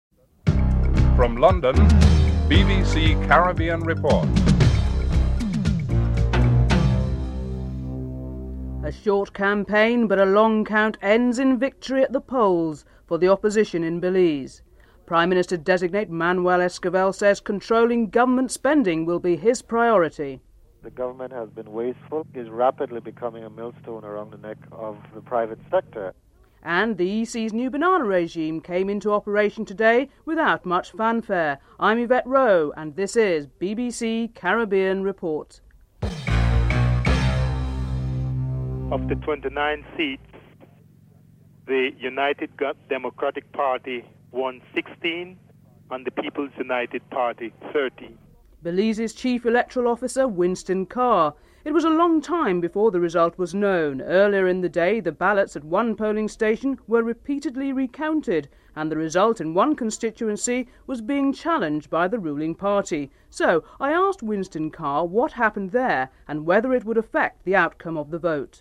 1. Headlines (00:00-00:45)
Interview with Manuel Esquival, Prime Minister designate.